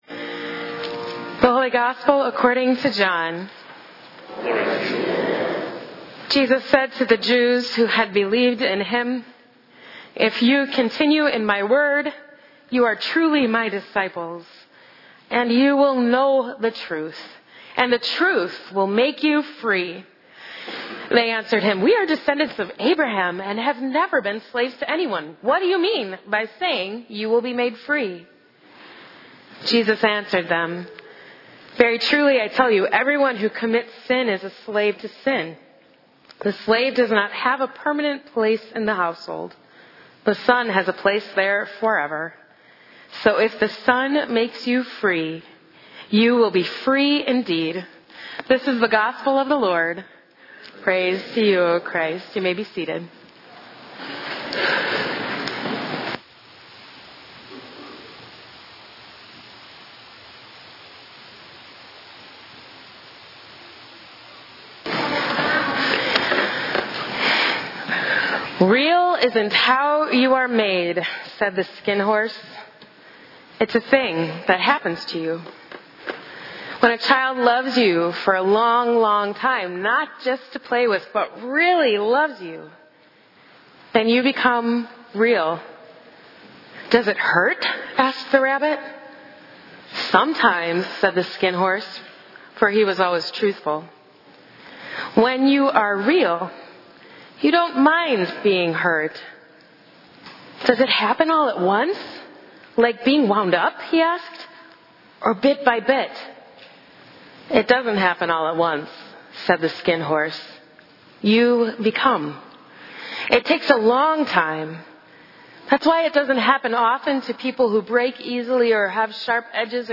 Oct 28, 2018 - Reformation Sunday - 9:30 am - Saint Andrew Lutheran Church
Recent Sermons